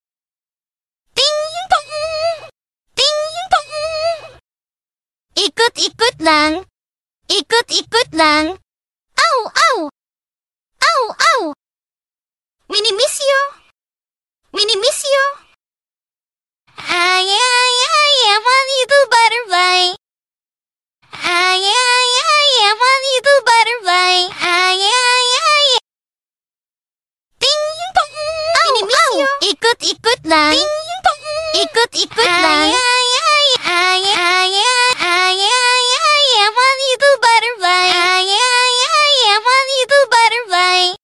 New Ruby skills sound effects sound effects free download